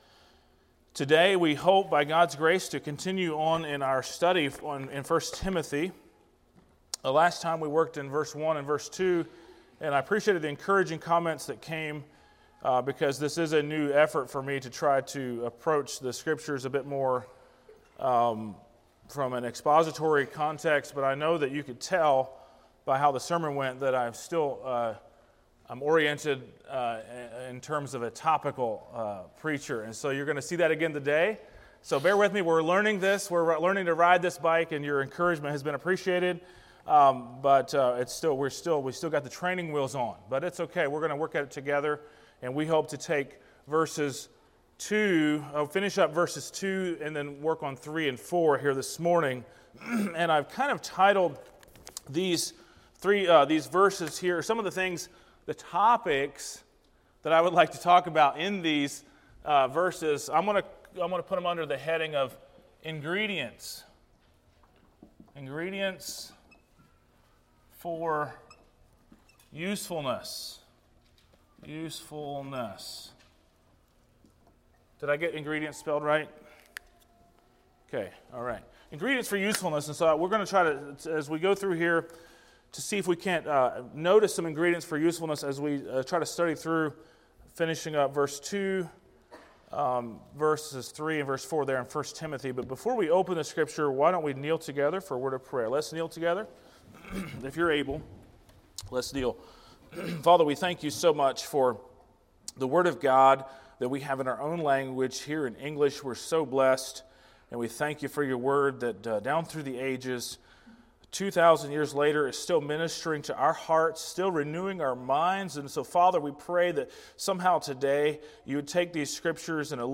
Sermons - Blessed Hope Christian Fellowship